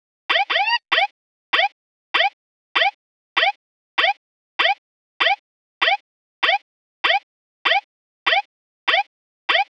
Hands Up - Siren 01.wav